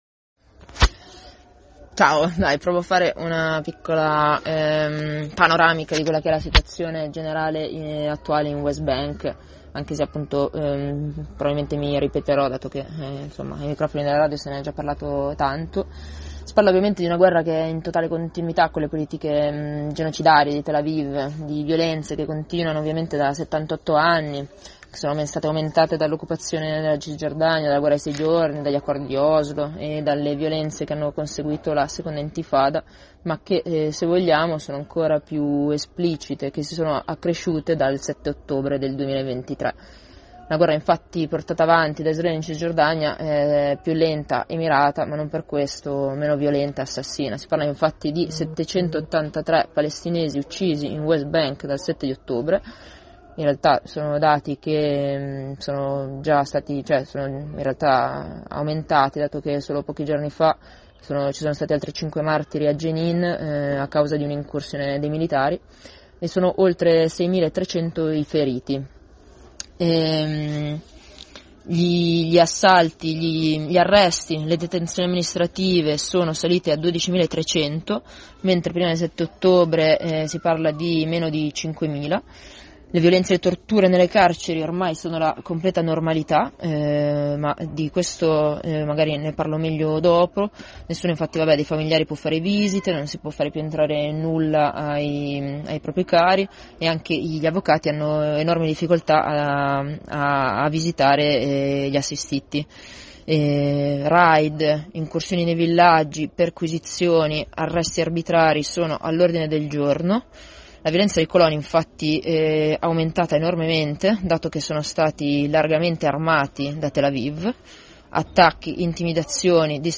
Ci giunge un aggiornamento da parte di una compagna che si trova in Cisgiordania a proposito della situazione sempre più difficile della popolazione della West Bank occupata .